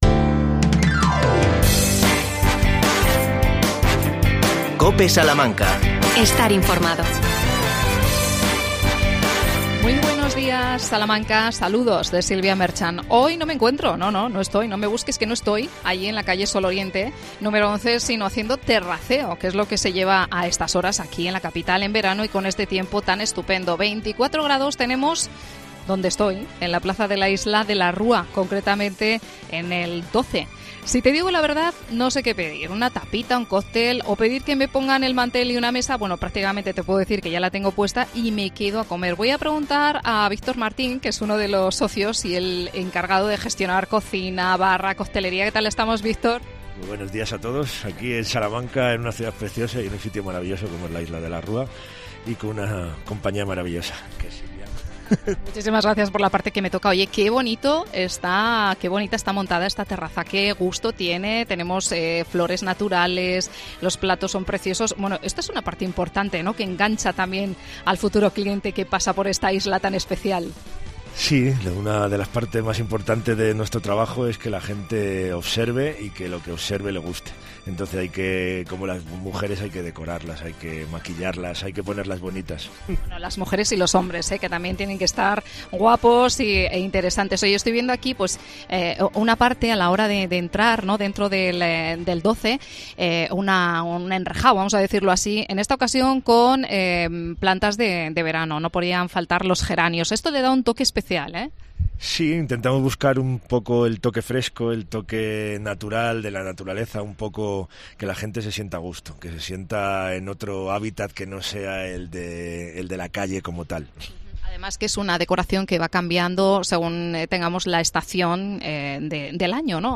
AUDIO: Está de moda el terraceo. Desde el DOZE.